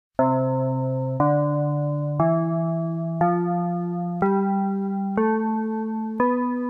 ESCALA MUSICAL CON NOMES
Tamén podemos escoitar como soa esta mesma escala facendo clic no seguinte audio:
ESCALA_DO-SI_PIANOcorta.mp3